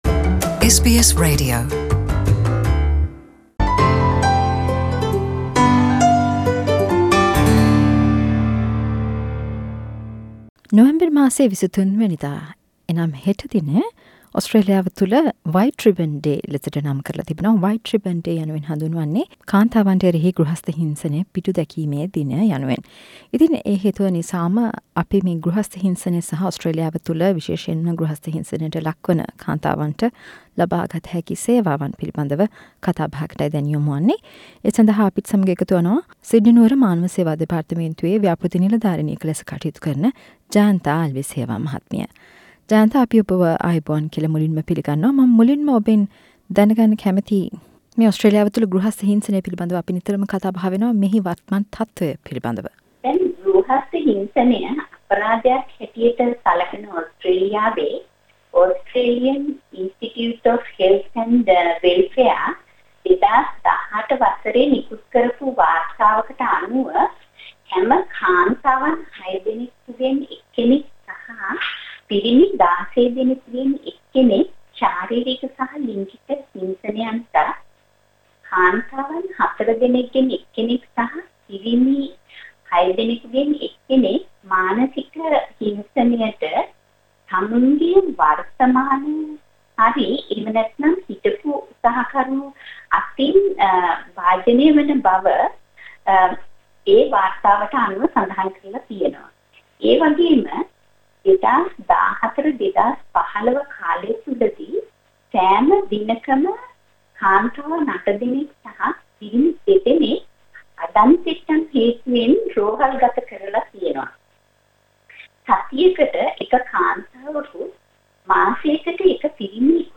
සාකච්චාවක්